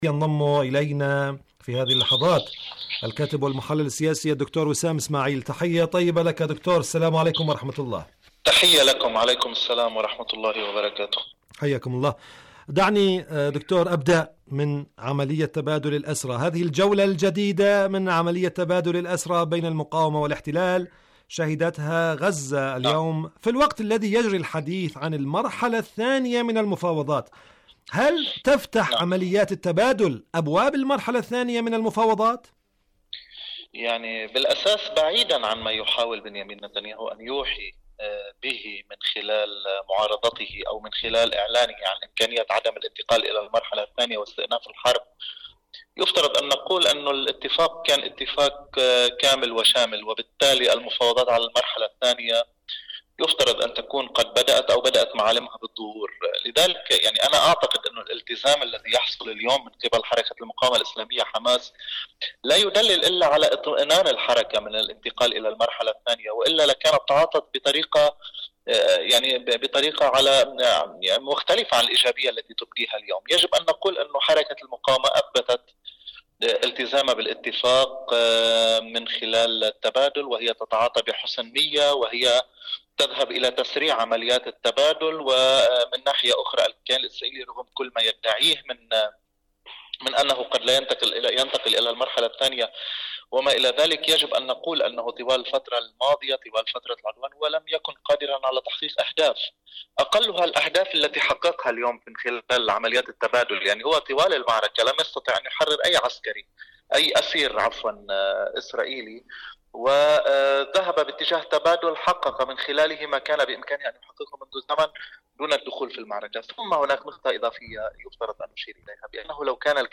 مقابلات برامج إذاعة طهران العربية برنامج فلسطين اليوم مقابلات إذاعية القدس الشريف كيان الاحتلال المسجد الاقصى ما بين غزة ولبنان وداع القادة ورسائل الطوفان شاركوا هذا الخبر مع أصدقائكم ذات صلة ما بين غزة ولبنان، وداع القادة ورسائل الطوفان..